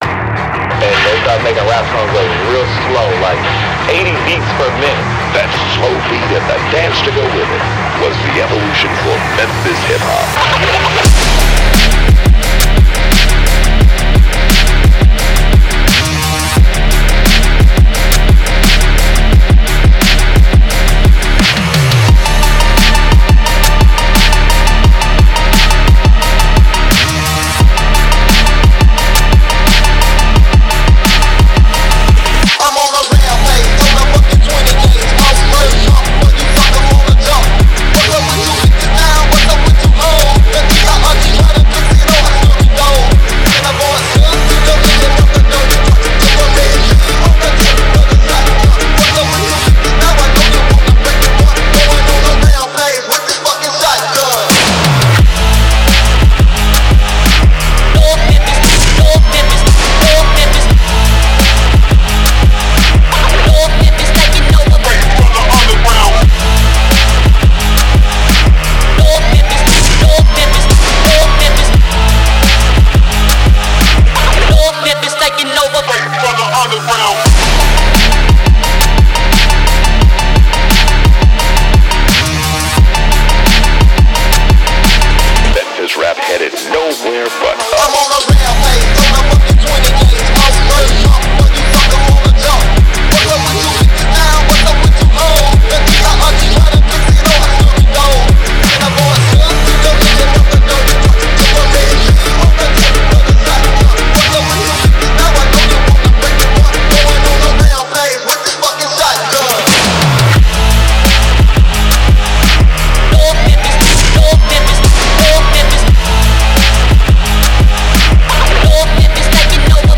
Фонк музыка
Phonk музыка